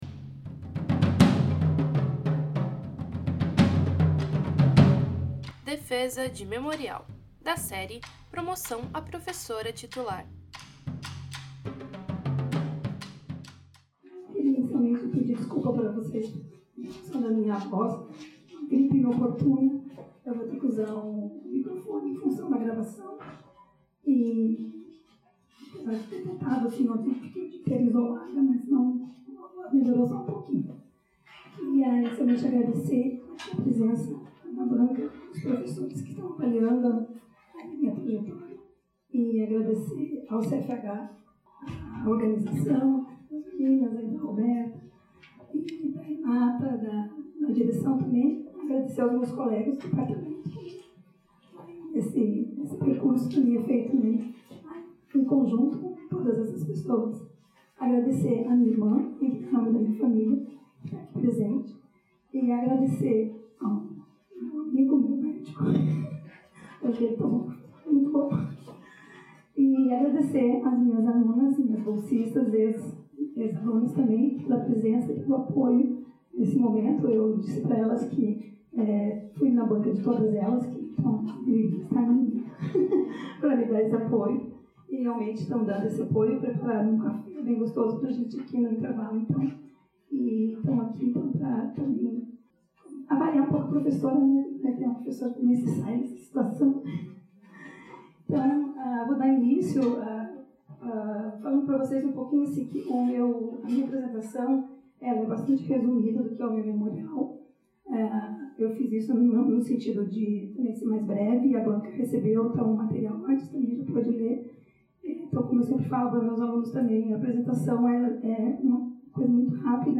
no Auditório do MArquE. Área de atuação: Psicologia Educacional e Psicologia Ambiental.